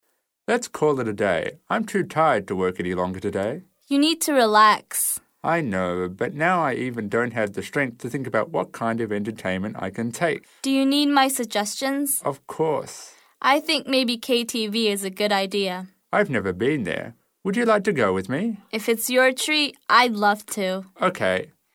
英语口语900句 11.07.对话.1.相约去KTV 听力文件下载—在线英语听力室